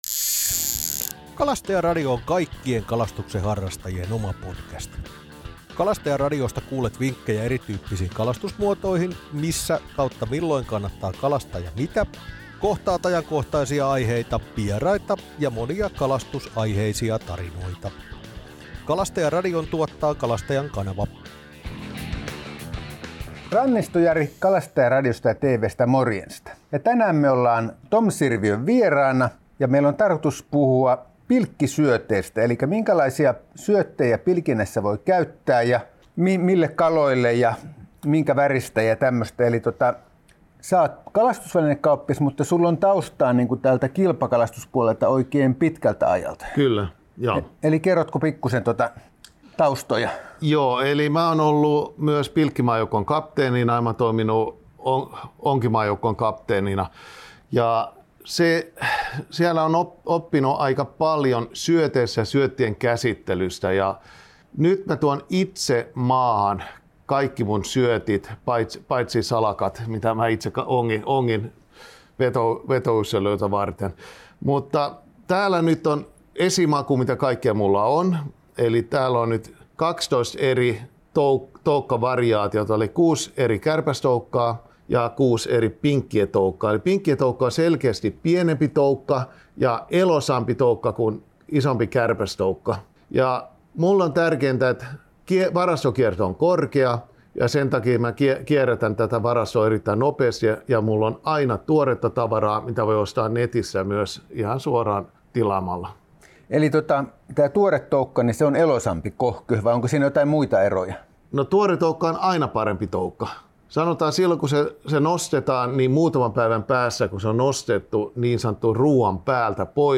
Kuuntele, kun toimittaja